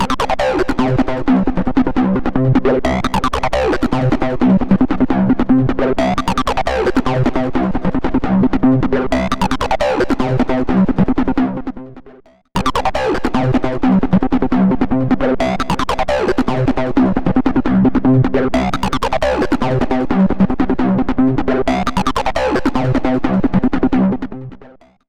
В файле записаны железный Virus C is в звуковую карту за 2000€ и эмулятор через звуковую карту Behringer за 90€.
Вложения emu vs hardware Impact MS V2.mp3 emu vs hardware Impact MS V2.mp3 984,7 KB · Просмотры: 1.989